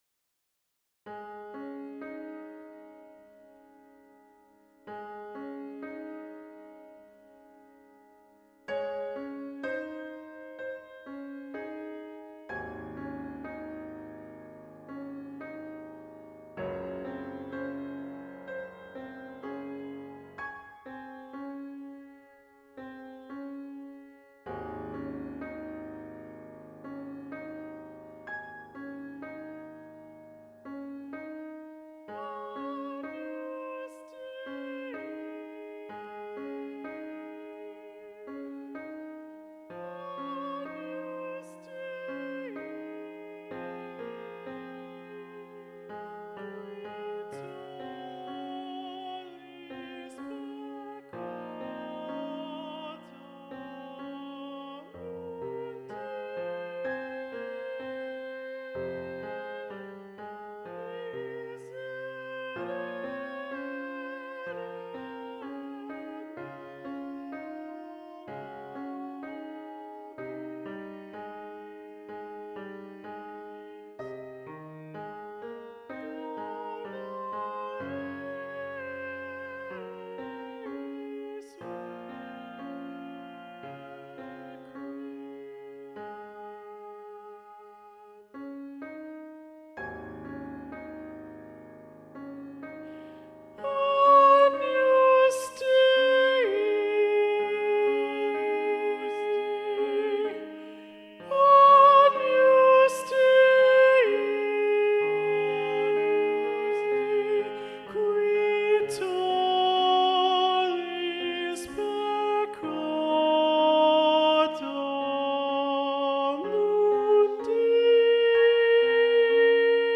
Video Only: Agnus Dei - Alto Predominant